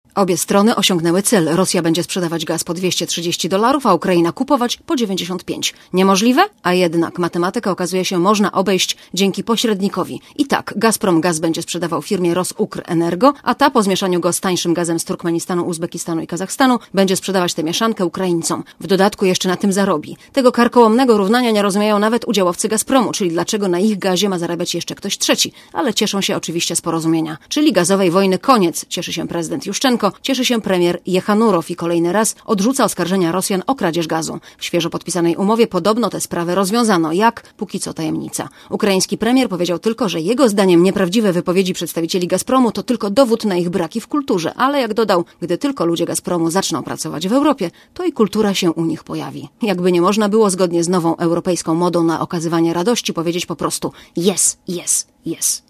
Źródło: PAP Relacja reportera Radia ZET Oceń jakość naszego artykułu: Twoja opinia pozwala nam tworzyć lepsze treści.